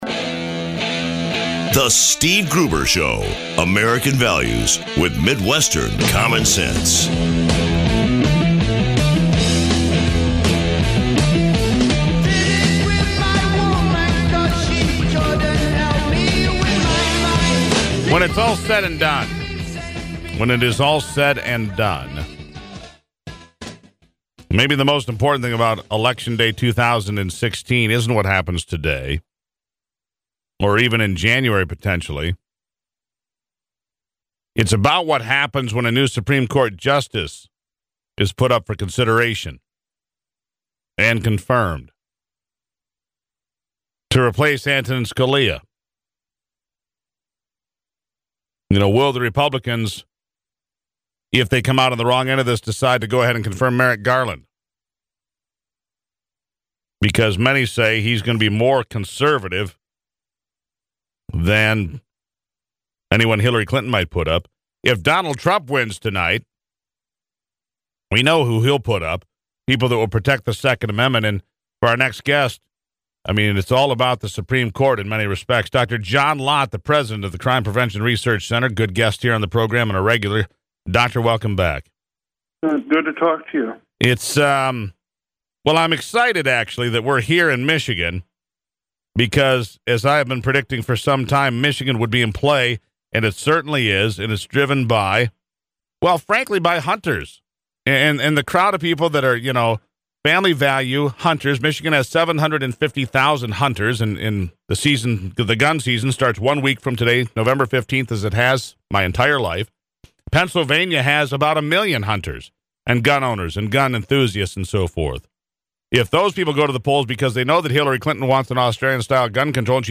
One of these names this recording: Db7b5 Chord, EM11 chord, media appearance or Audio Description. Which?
media appearance